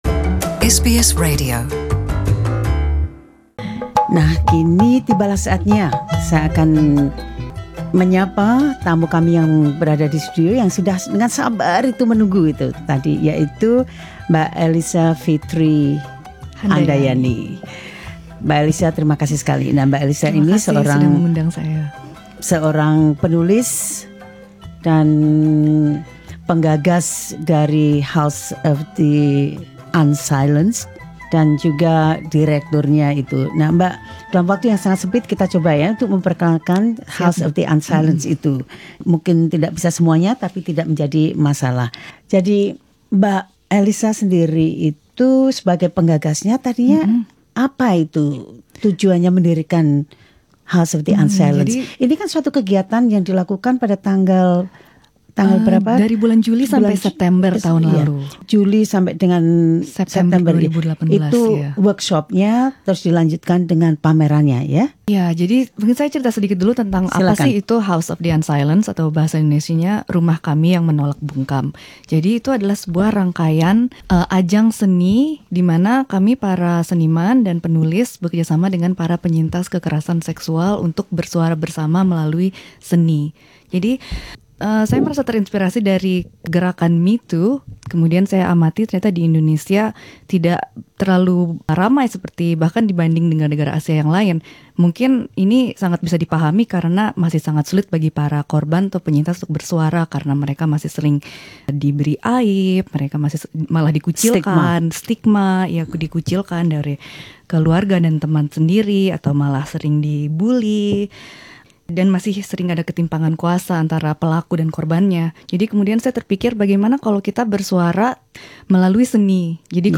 in the SBS studio